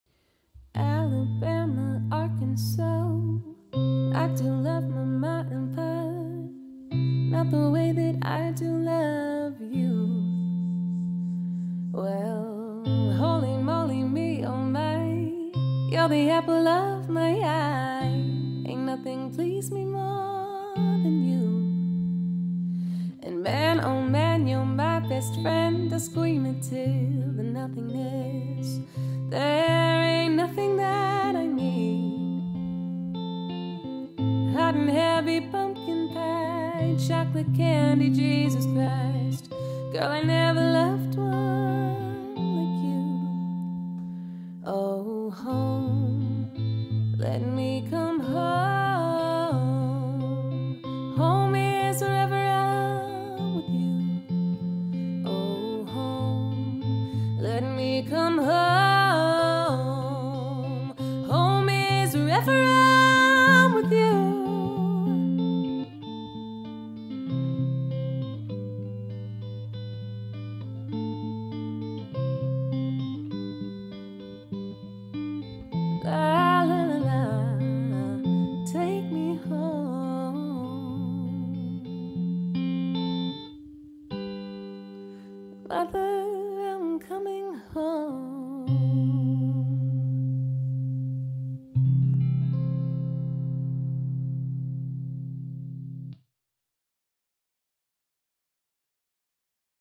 Female acoustic looper for Melbourne Weddings and Corporate Events
Vocals | Guitar | Looping | DJ | Duo Option